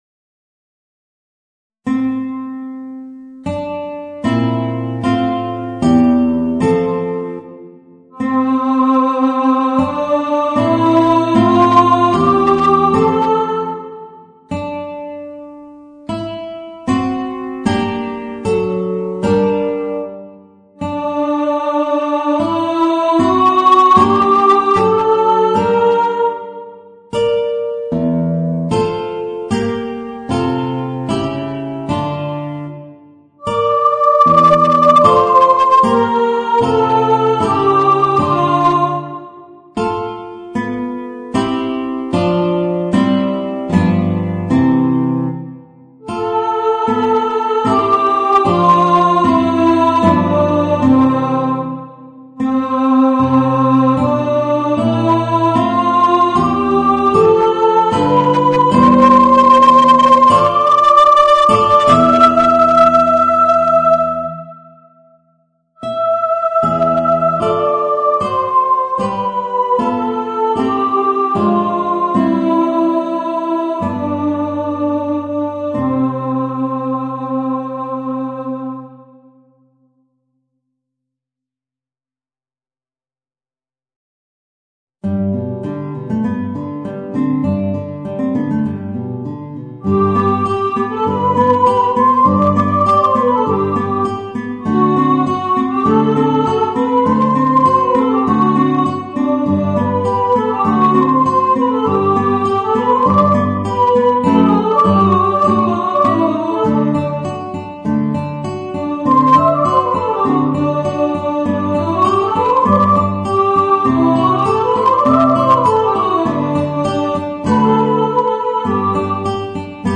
Voicing: Guitar and Mezzo-Soprano